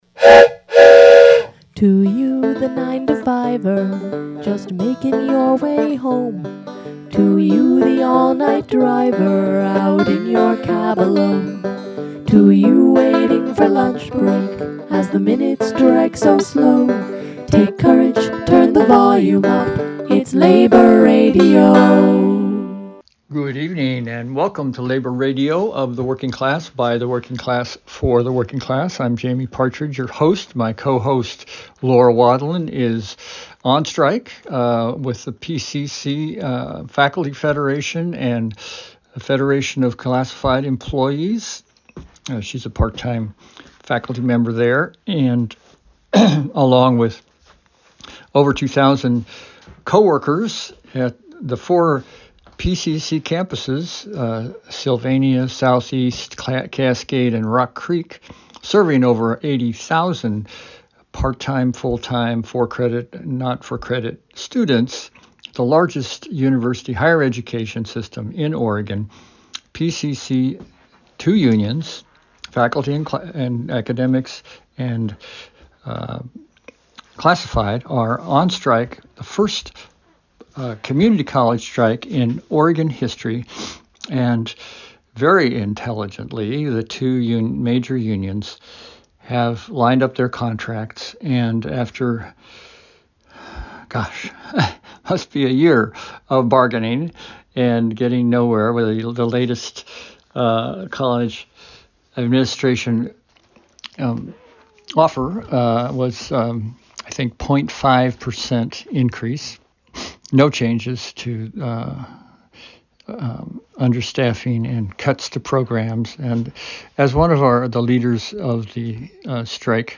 On the Portland Community College strike line with workers, students and community allies.